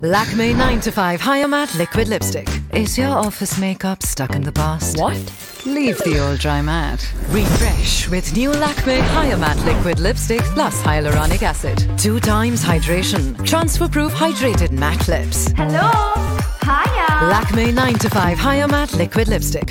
Audio Native Ads.mp3